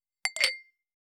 280食器をぶつける,ガラスをあてる,皿が当たる音,皿の音,台所音,皿を重ねる,カチャ,ガチャン,カタッ,コトン,ガシャーン,カラン,カタカタ,チーン,カツン,
コップ効果音厨房/台所/レストラン/kitchen食器